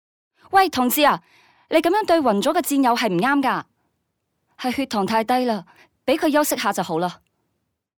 多语种样音集 – 各类展示了不同语言演绎的不同人声。
声优团队精准把握角色性格与年龄特征，通过差异化声线与细腻演绎，生动塑造出个性鲜明的动画形象，为观众呈现一场兼具趣味性与情感深度的视听之旅。
声优利用刚强的声音和强势的表现手法，准确传达了角色的战斗精神和好胜心。